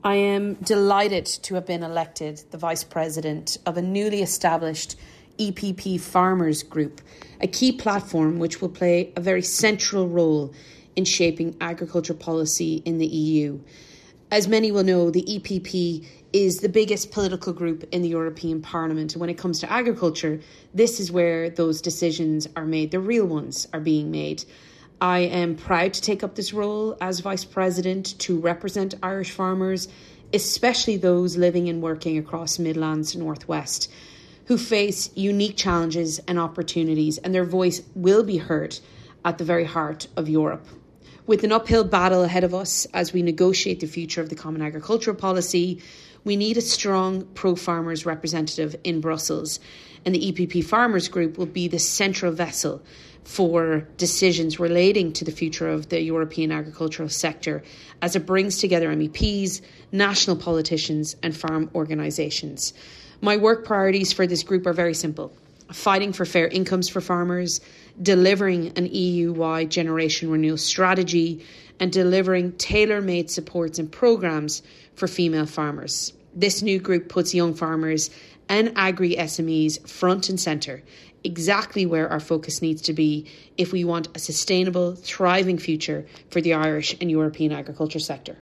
Ms Walsh says she will ensure that the voice of farmers who are facing unique challenges will be heard in Europe: